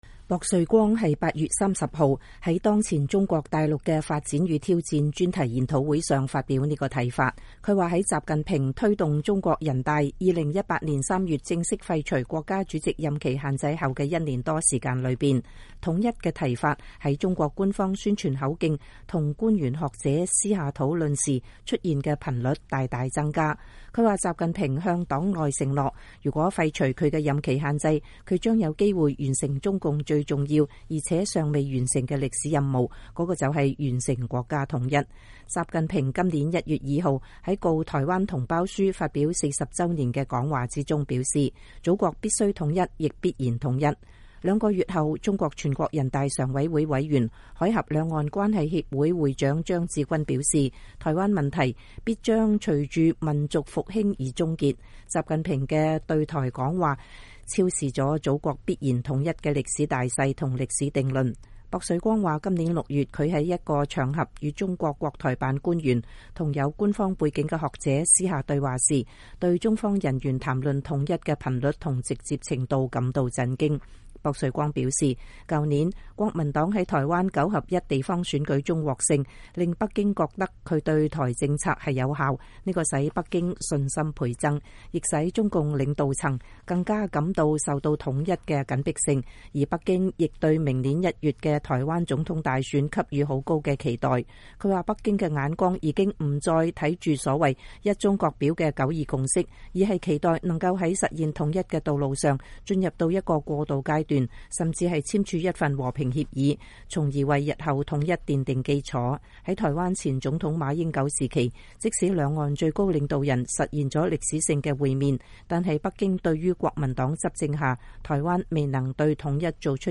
前美國在台協會主席薄瑞光(Raymond Burghardt)在台北參加“當前中國大陸的發展與挑戰”的研討會時發表演講。